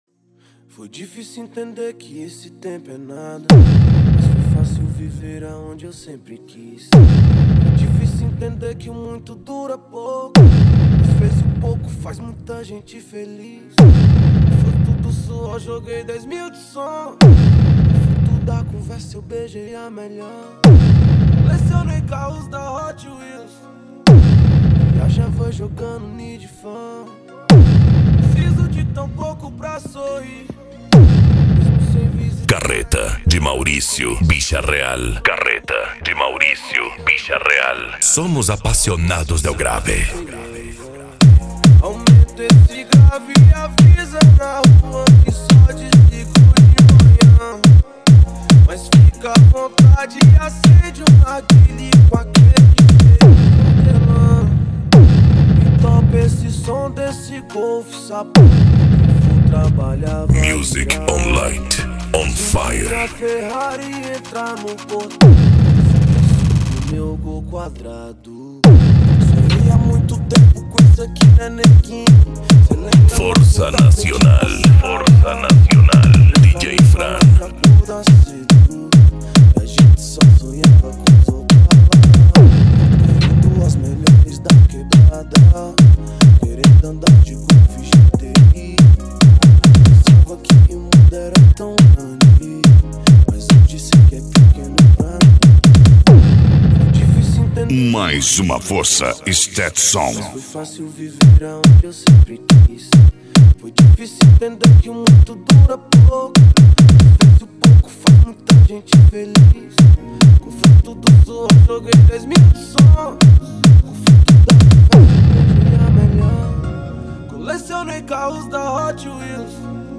Bass
PANCADÃO
Psy Trance
Remix